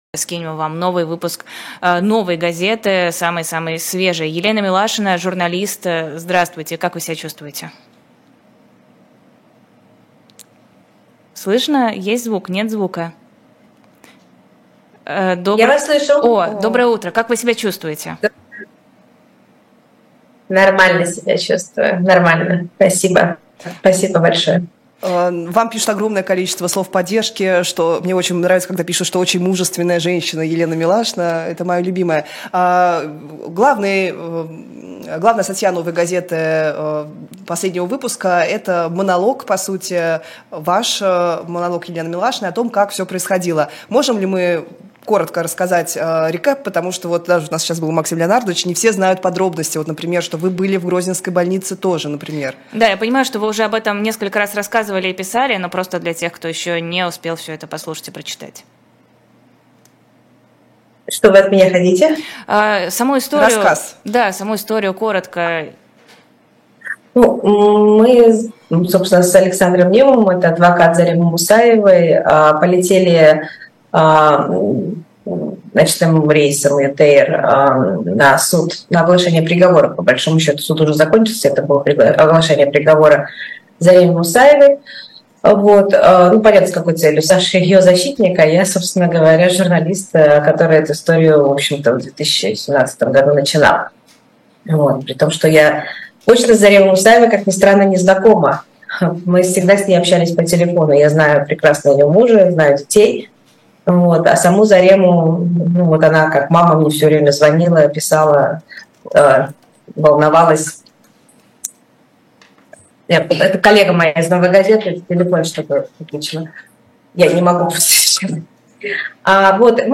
Эфир от 07.07.23